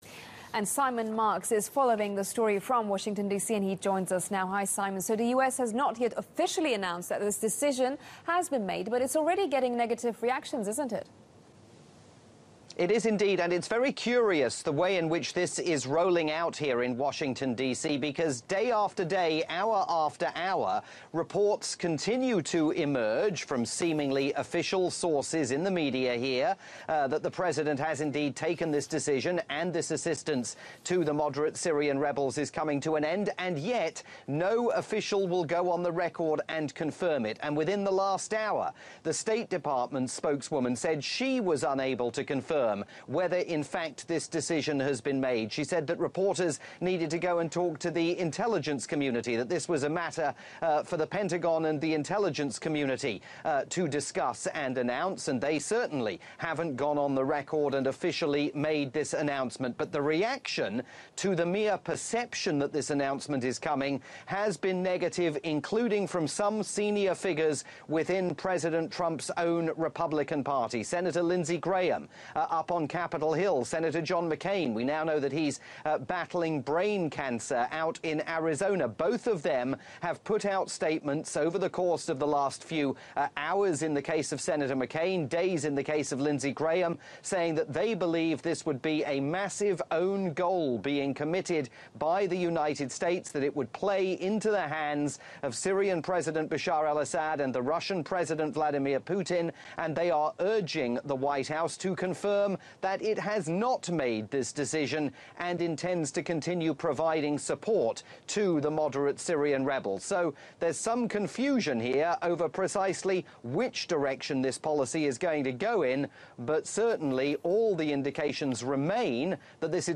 From Turkish global tv network TRT World.